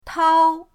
tao1.mp3